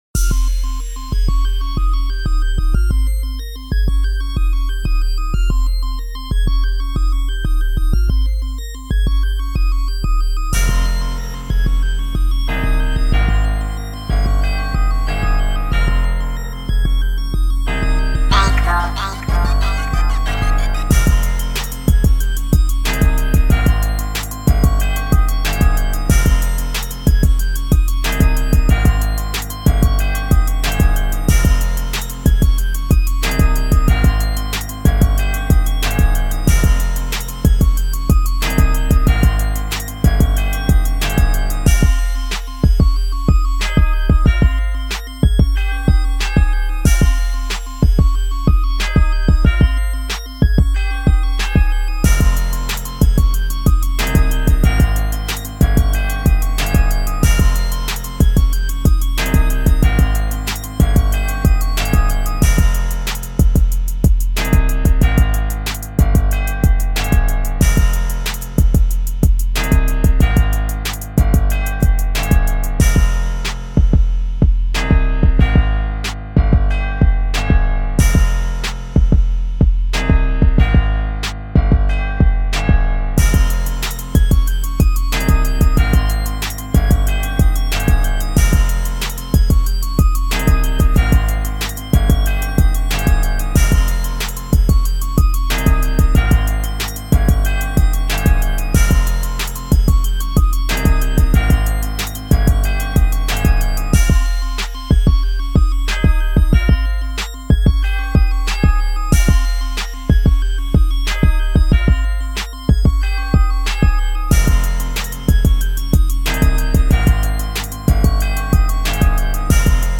Drill Instrumental